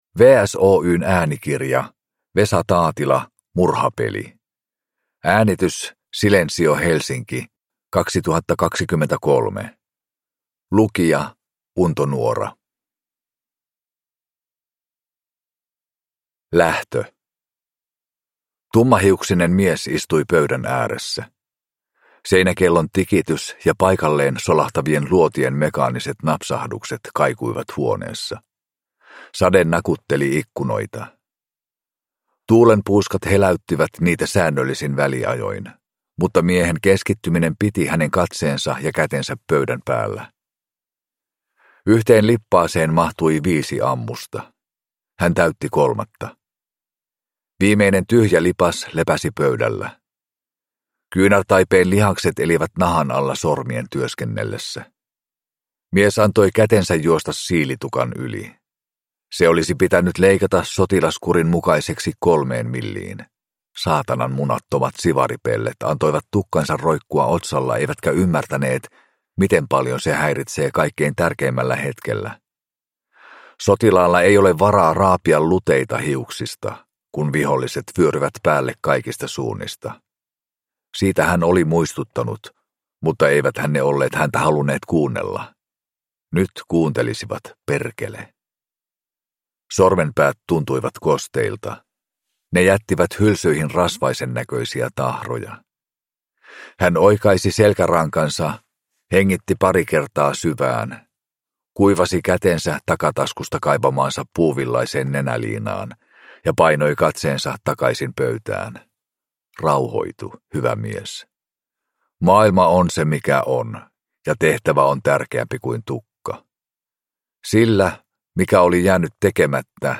Murhapeli – Ljudbok – Laddas ner